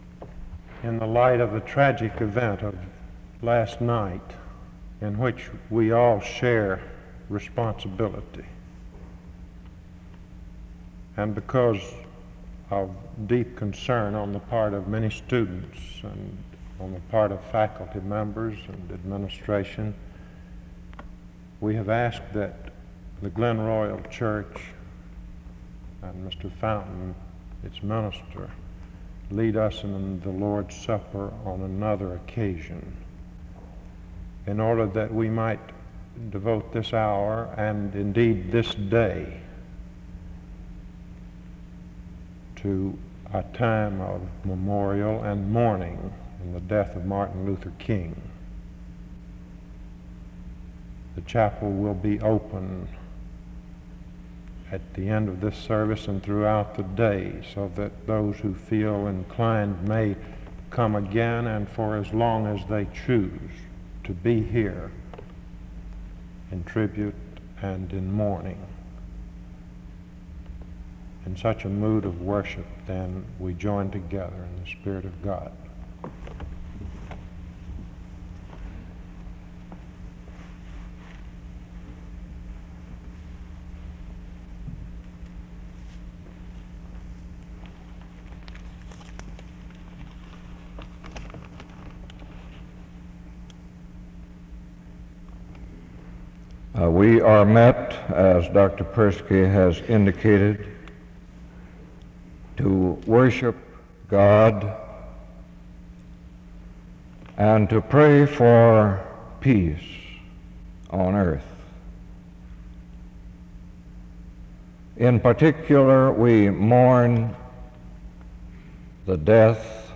This memorial service was held in honor of the late Martin Luther King, Jr. one day after his assassination.
The service starts with the somber announcement of the passing of Rev. Martin Luther King, Jr. from 0:00-1:16.
A prayer is offered from 3:45-9:03. Quiet reflection takes place from 9:05-12:05. Jeremiah 4:23-26 is read from 12:08-13:14.
Quiet reflection continues from 15:10-17:30.